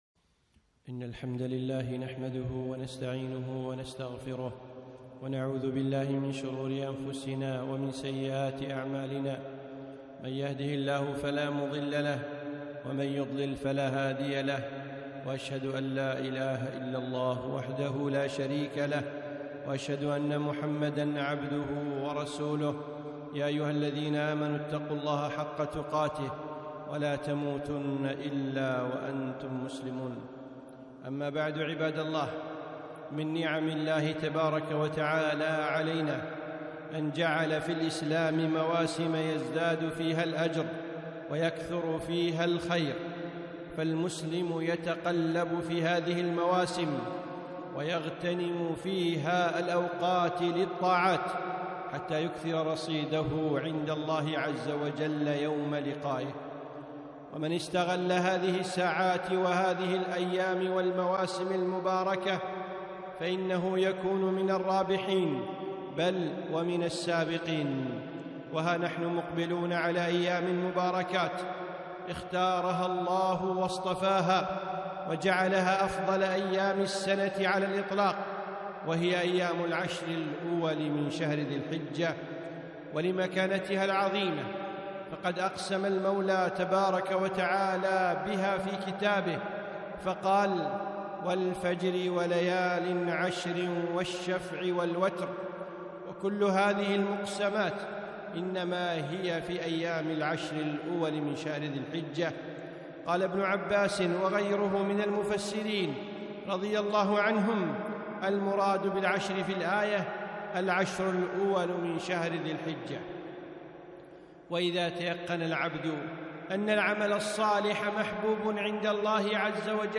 خطبة - غتنم العشر ووقر العلماء